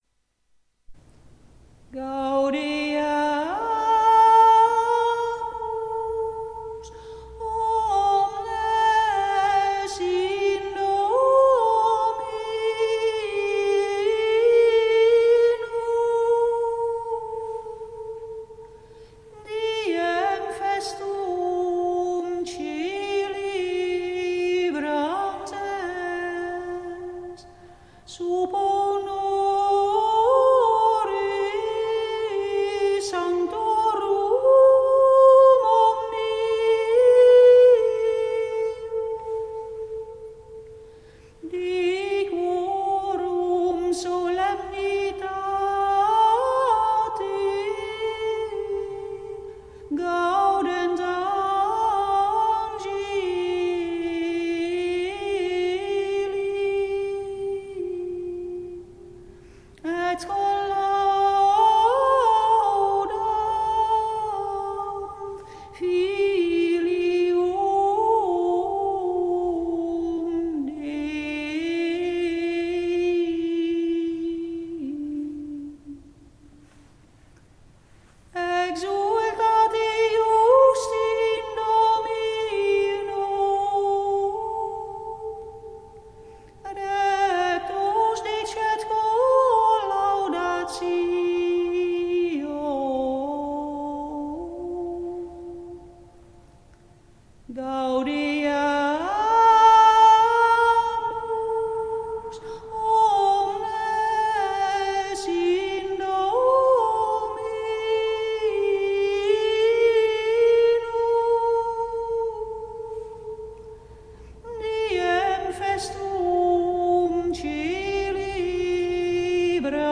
Gaudeamus omnes (Ps. 32), introitus  WMP   RealPlayer